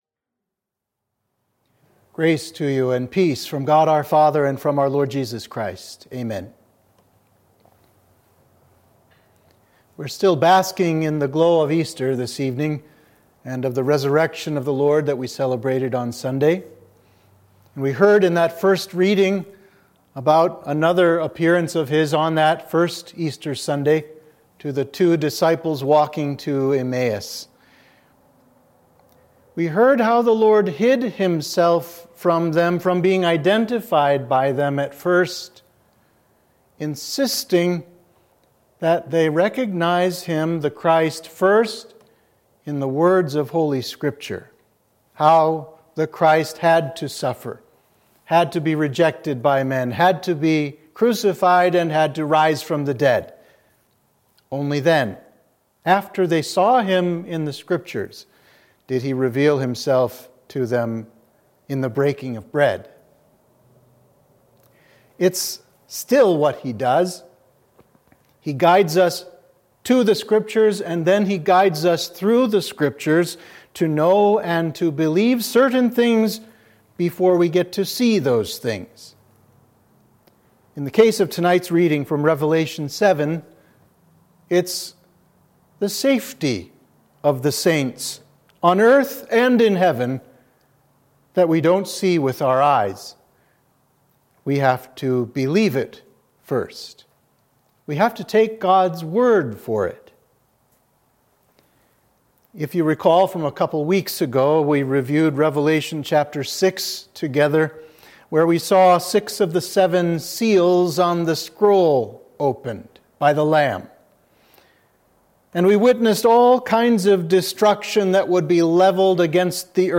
Sermon for Midweek of Easter